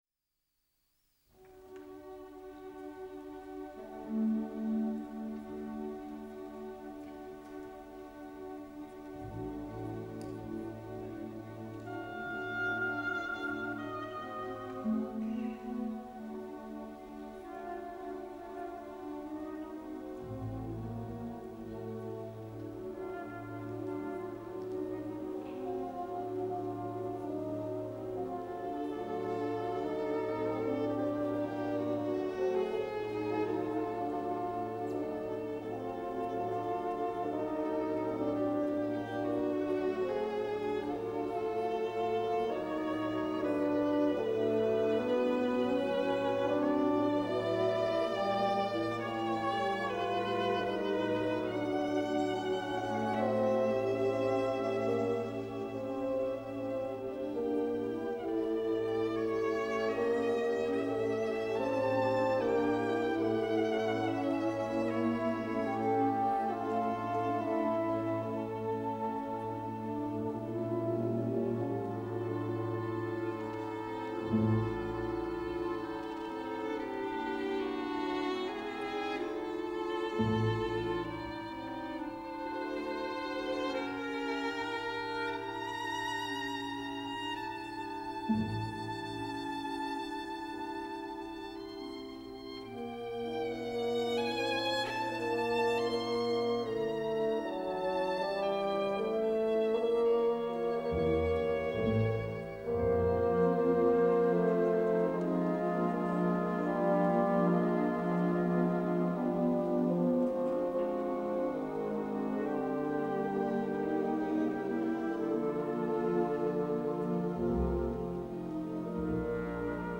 The legendary André Cluytens leading the Cologne Radio Symphony in this 1957 Radio studio performance of Roussel’s Bacchus et Ariane Suite Number 2 – recorded on January 28, 1957.